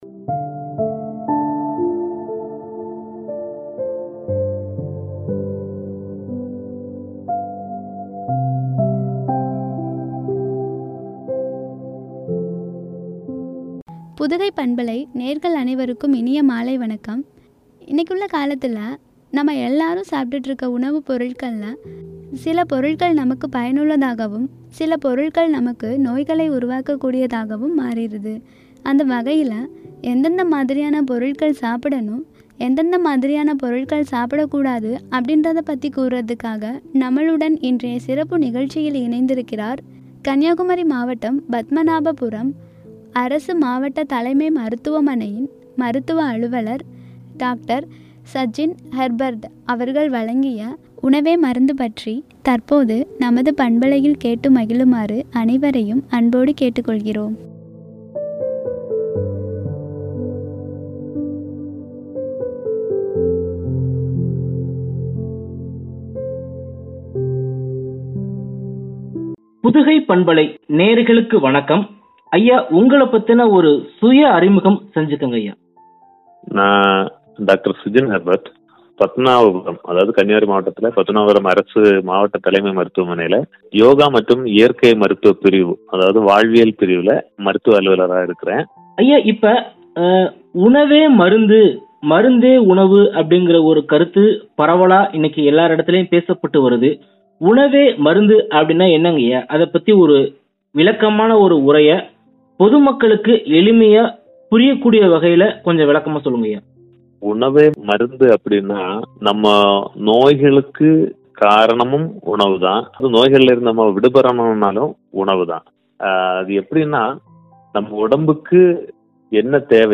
“உணவே மருந்து” குறித்து வழங்கிய உரையாடல்.